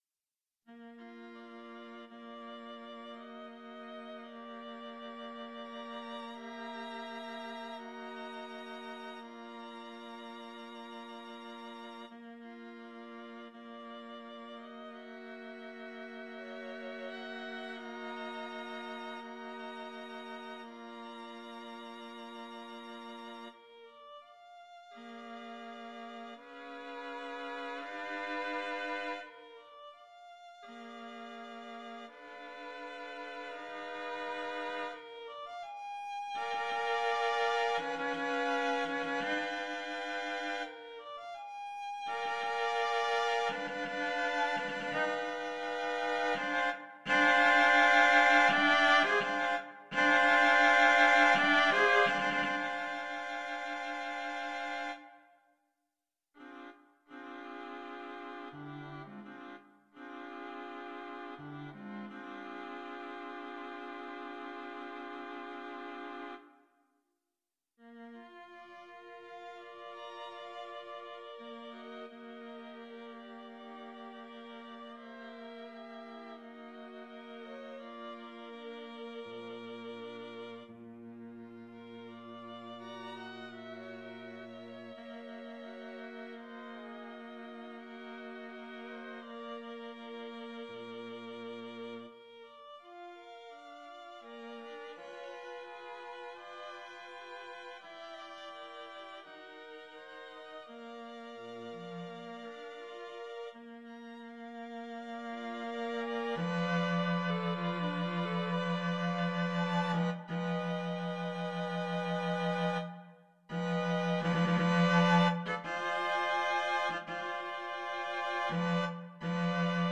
Sagittal Notation Score and Computer Playback
Toccata in Bb String Quartet Mixed Sagittal Recording
This version is that piece translated into a string quartet setting, using Mixed-Sagittal notation, which I think is ultimately easier to read and provides more useful tuning information to the musicians. The recording also includes the correct pitch-bends for every note in the piece, so it should provide a fairly decent idea of what the music would sound like if musicians were to work it up and perform it. The string quartet sound is from Sibelius, and although I was initially unsure if I would like the sound with it’s automatic excessive vibrato, I think that the results actually work well. While the real strength of writing in just intonation is generating potentially very complex yet vibrantly resonant harmonies is obscured by a lot of vibrato (straight-tones bring out the resonances better), the vibrato does emphasize the melodic content well, and it looks like there’s enough good melodic content there that it’s effective.
toccata-in-bb-string-quartet-mixed-sagittal.mp3